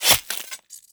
flesh1.wav